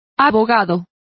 Complete with pronunciation of the translation of solicitor.